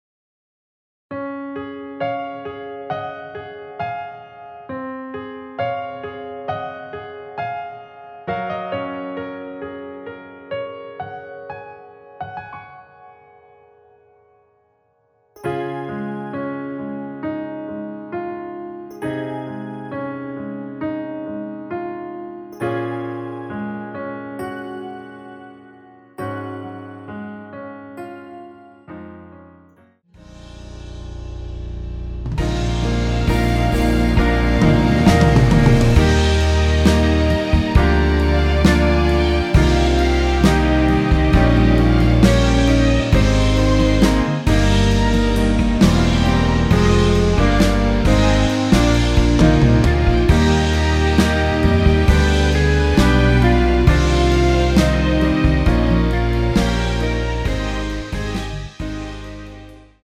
원키에서(-2)내린 멜로디 포함된 MR입니다.
Db
앞부분30초, 뒷부분30초씩 편집해서 올려 드리고 있습니다.
중간에 음이 끈어지고 다시 나오는 이유는